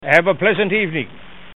Category: Radio   Right: Personal
Tags: Radio The Curse Of Dracula Play Horror Bram Stoker